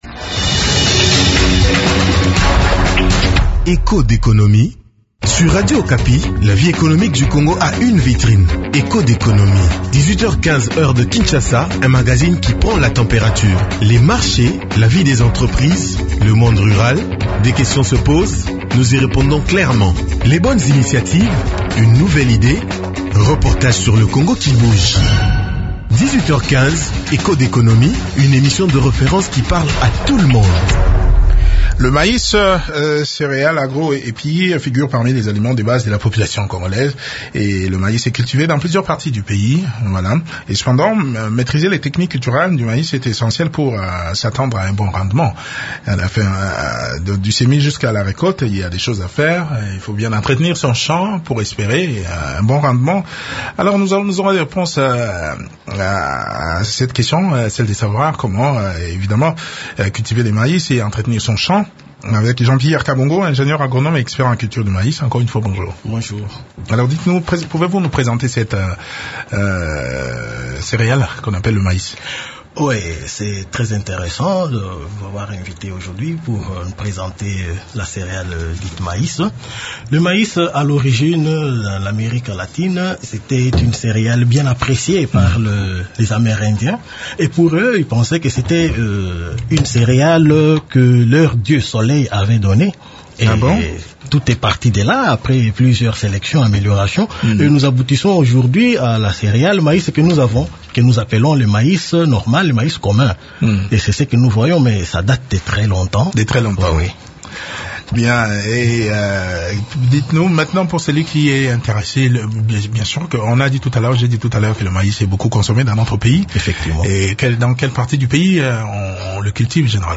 discute de ce sujet avec l’ingénieur agronome